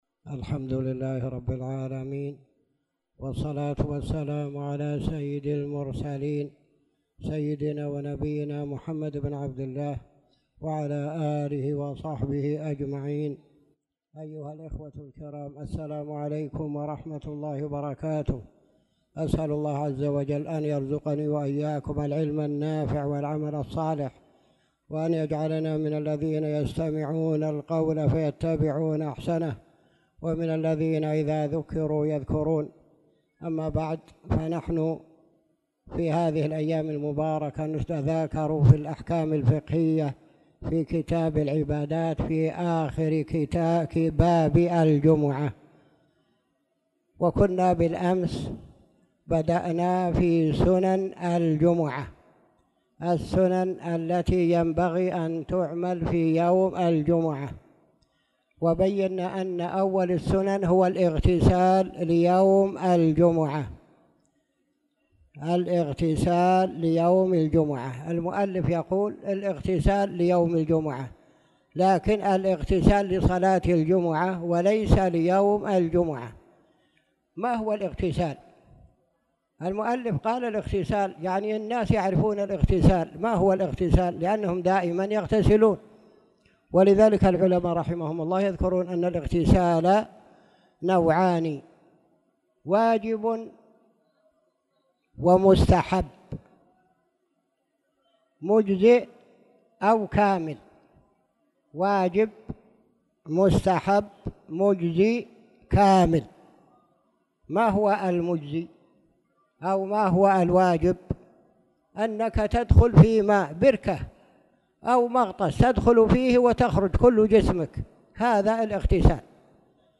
تاريخ النشر ١٥ شعبان ١٤٣٧ هـ المكان: المسجد الحرام الشيخ